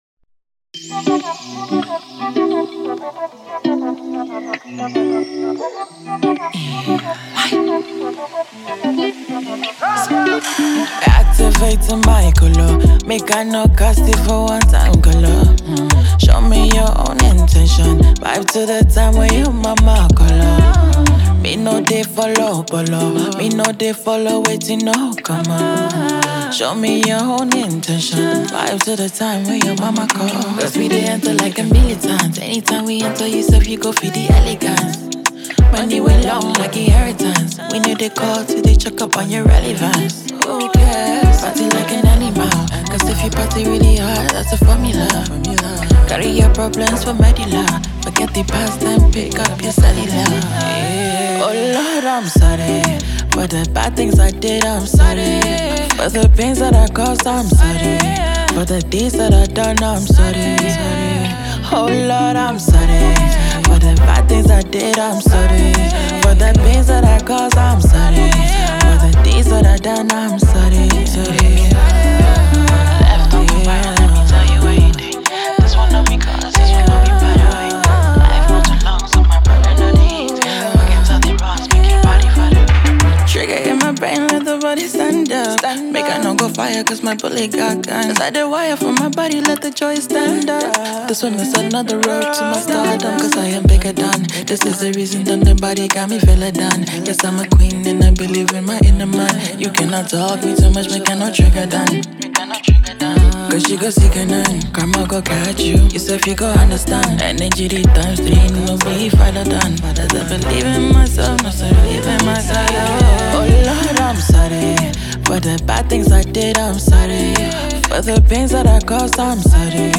emotive vocals